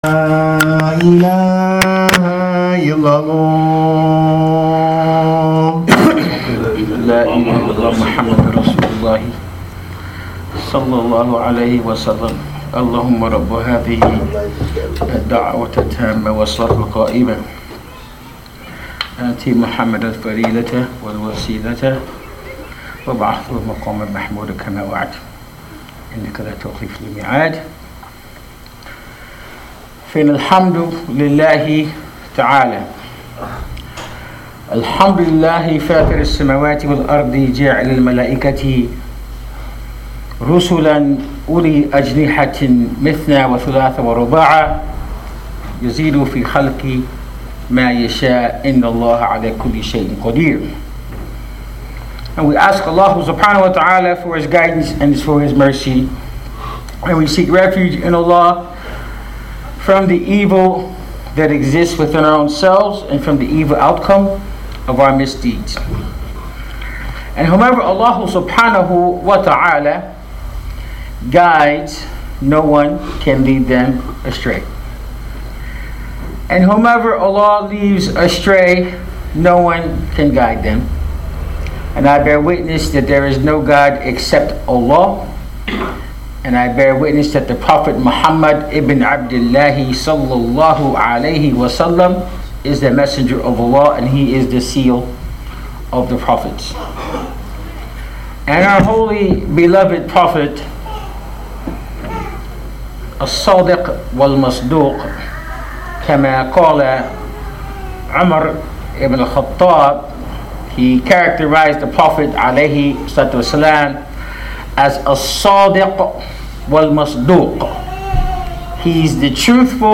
Audio Khutba